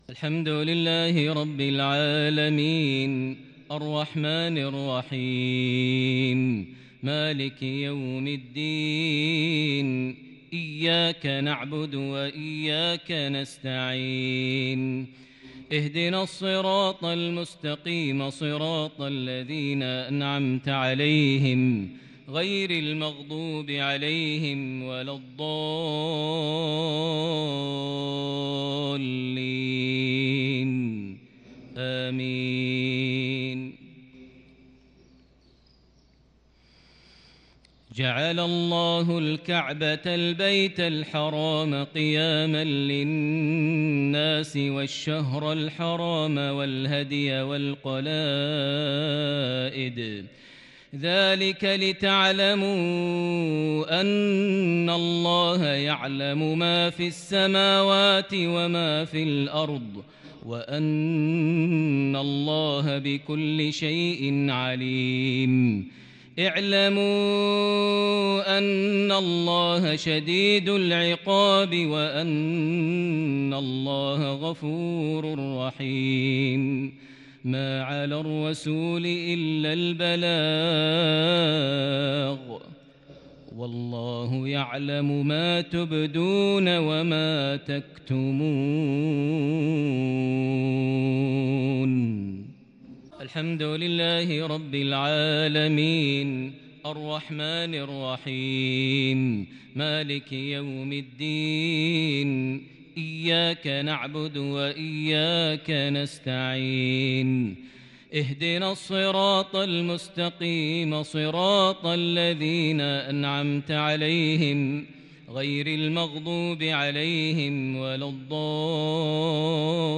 مغربية كردية فذه من سورتي المائدة + الفتح | 26 ذي الحجة 1442هـ > 1442 هـ > الفروض - تلاوات ماهر المعيقلي